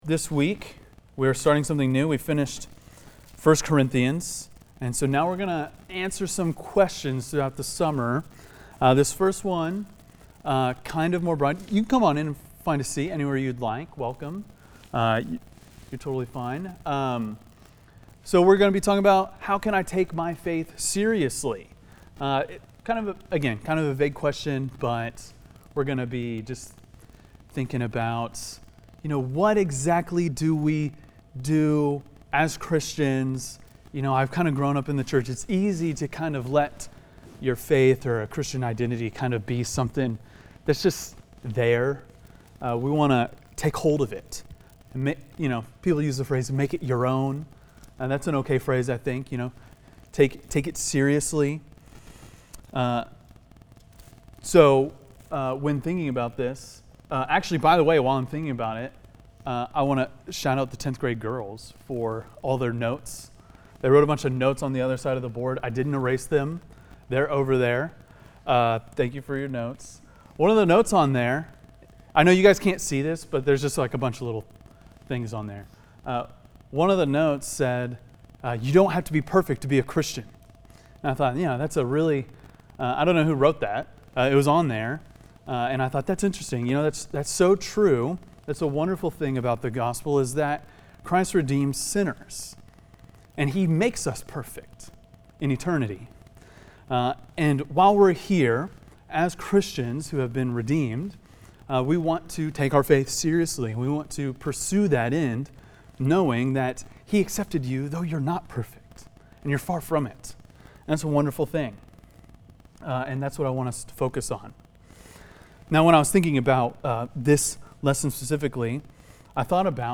preaches topically to answer the question